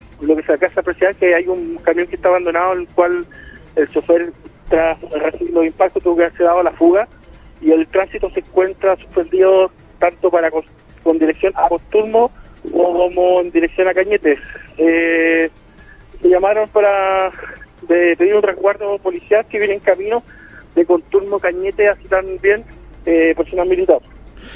Tras el ataque armado llegó al lugar personal de Control de Orden Público de Carabineros, mientras que personas con motosierra comenzaron a cortar los troncos para despejar la ruta.